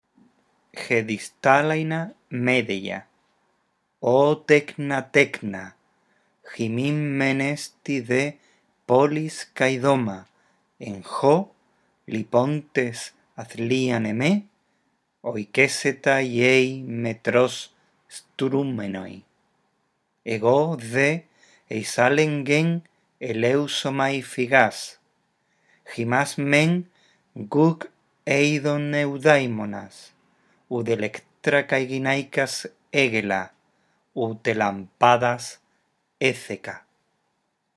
Lee el texto en voz alta, respetando los signos de puntuación.